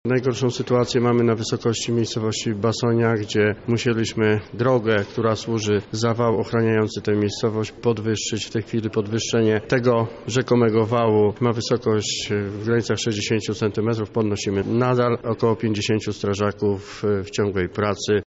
O sytuacji w Józefówie nad Wisłą mówi Grzegorz Kapica, wójt gminy Józefów.